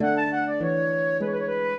flute-harp
minuet6-6.wav